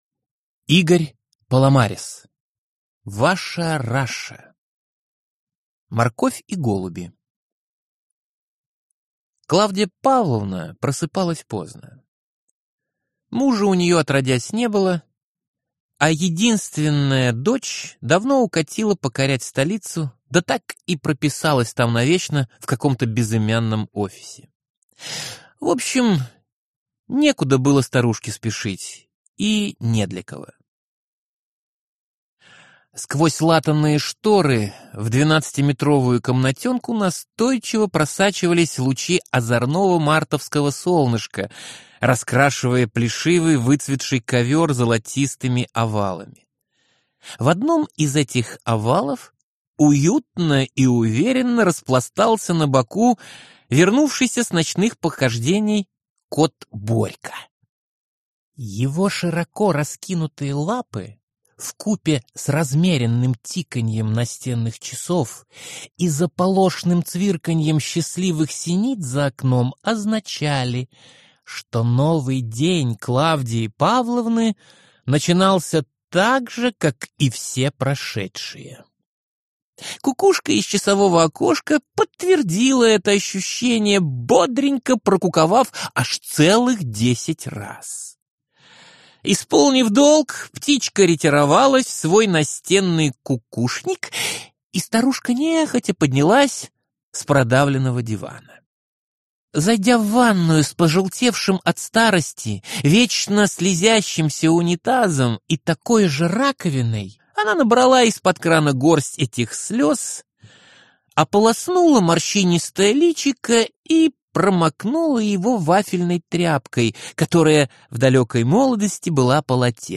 Аудиокнига Ваша Раша | Библиотека аудиокниг